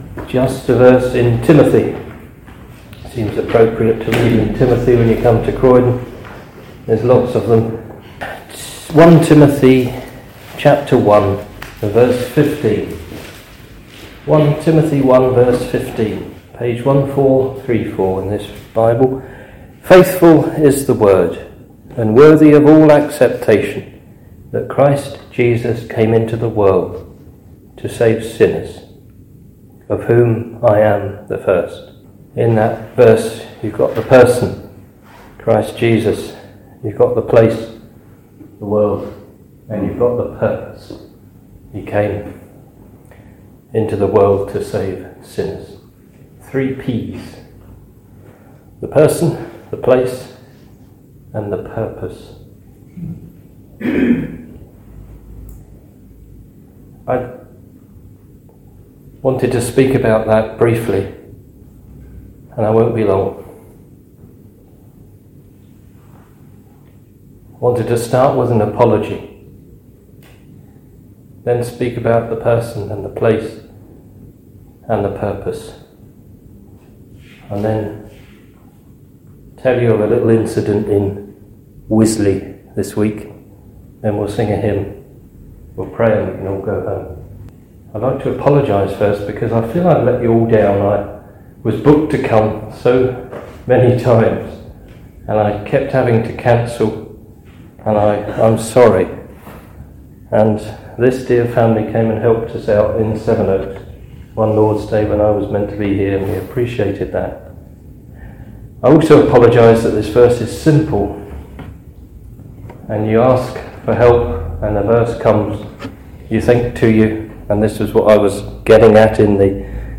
Jesus Christ came into the world to save sinners. This Gospel preaching looks at the Person of Jesus, the place He entered, and His saving purpose—to rescue sinners.